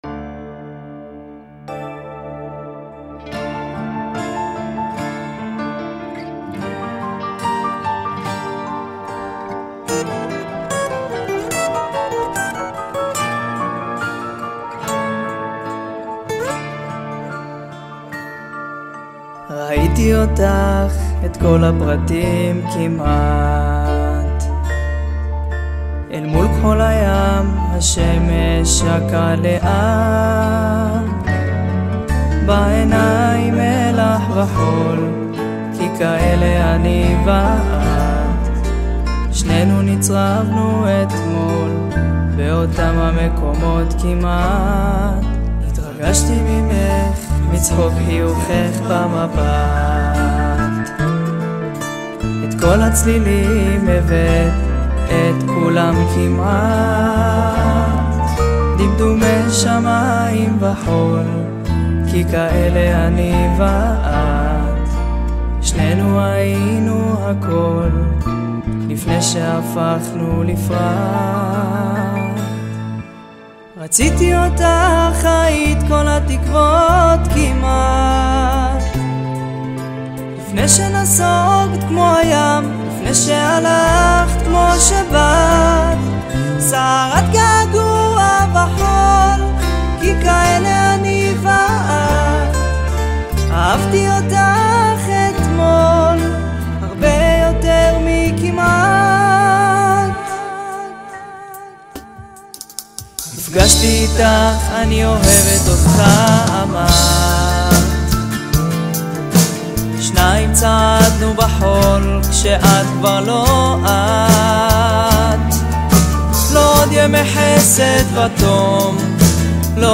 העיבוד העשיר
והוא מגיש אותו ברכות ועם רגש, שחודרים ישר ללב.